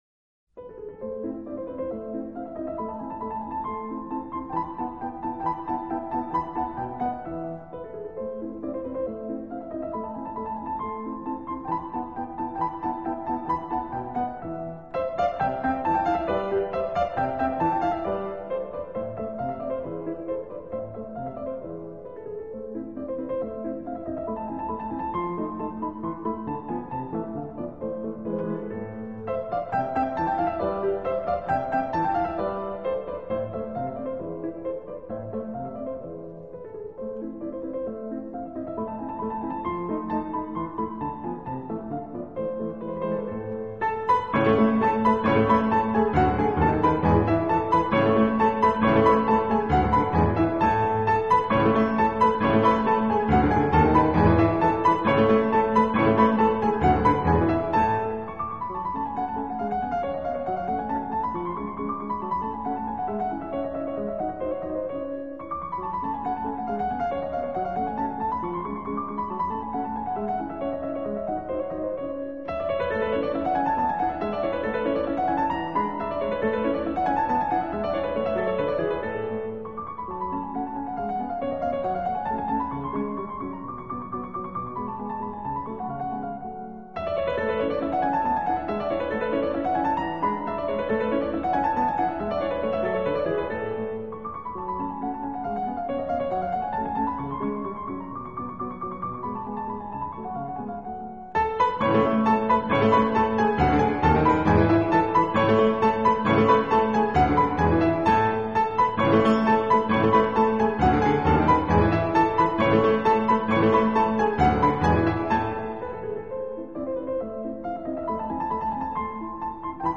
【纯音乐】
回旋曲 土耳其风格的小快板